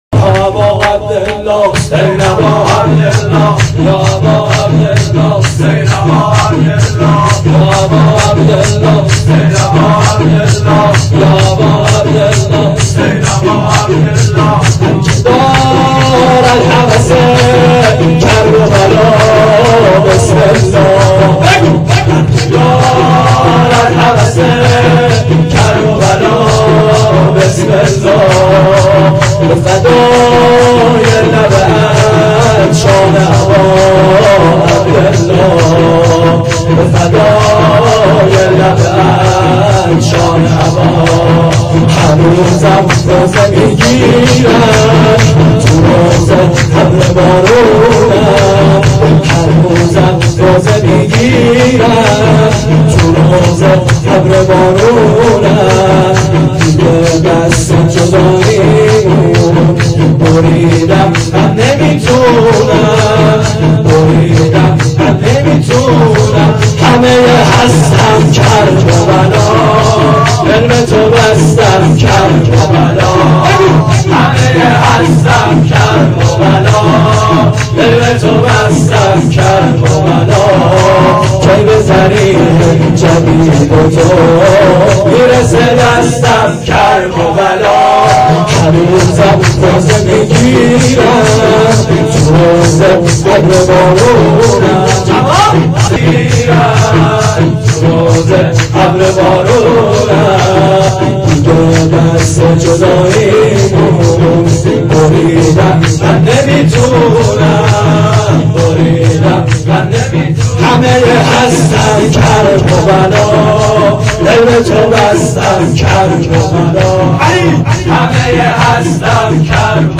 شور.wma